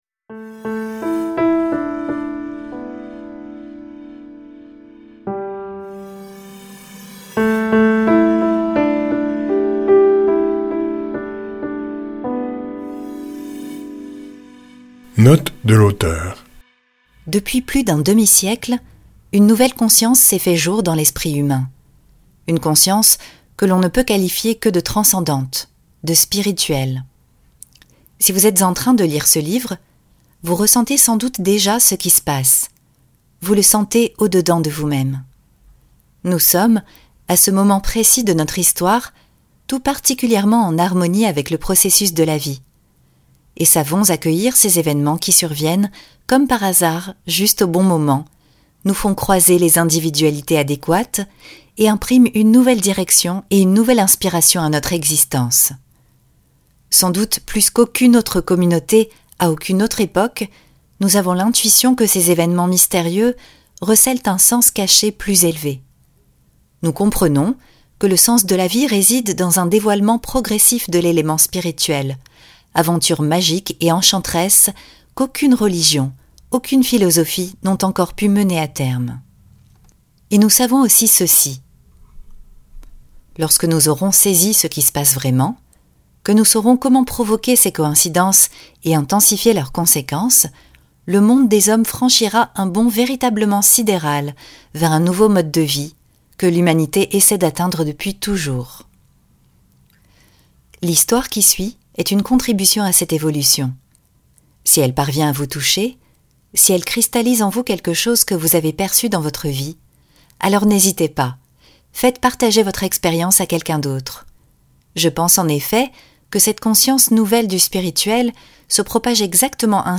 Extrait gratuit - La prophétie des Andes de James REDFIELD